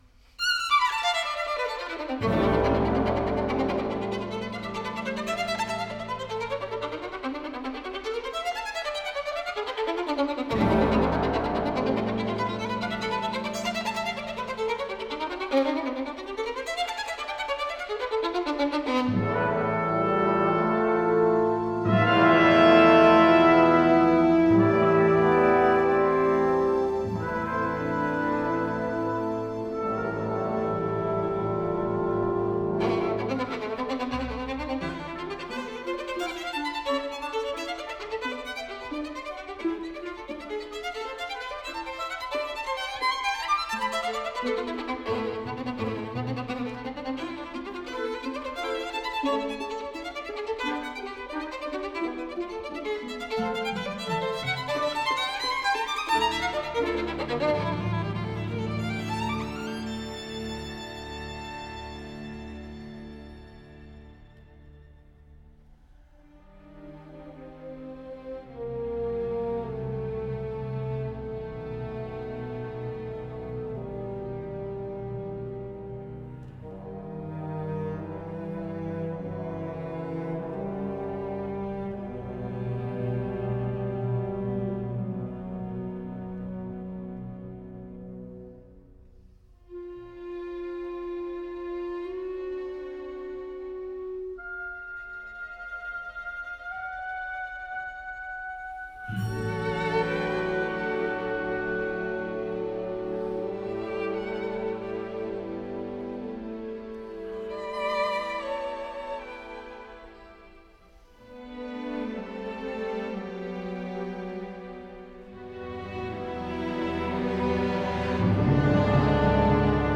violon